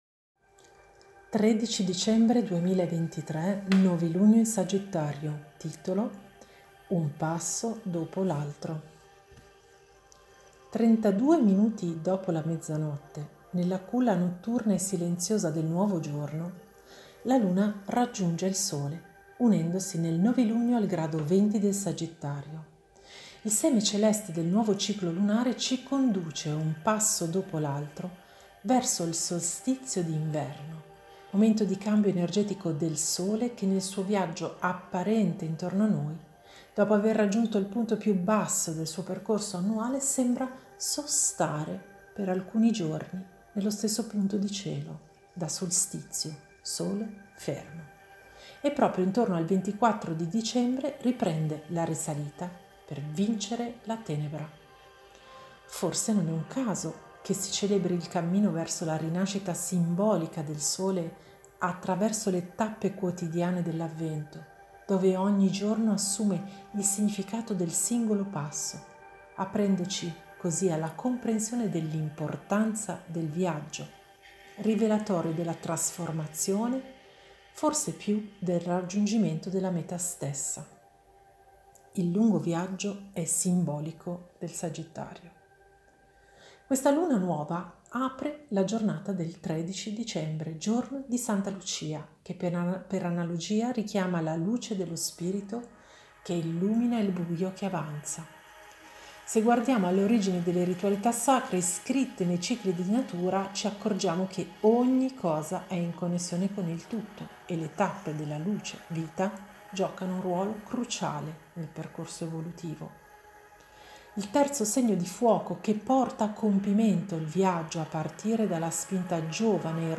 AUDIO ARTICOLO letto da me